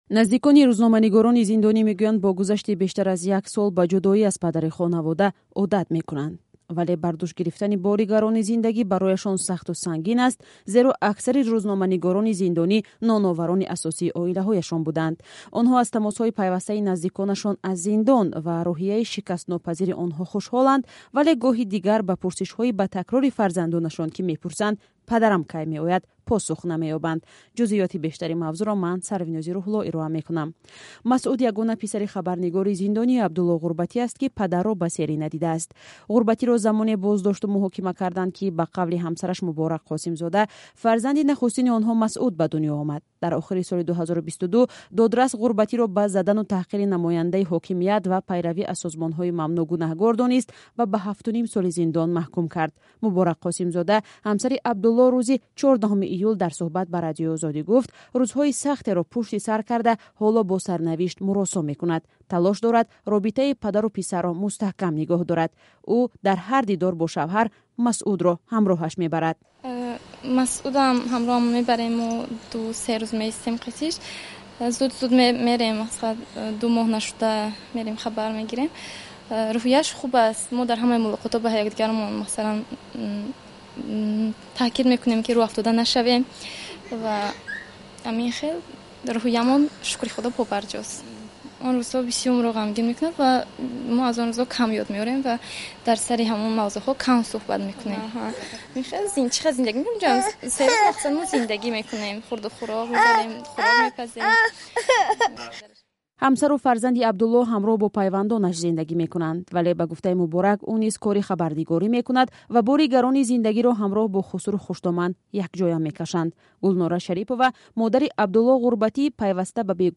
Гузоришҳои радиоӣ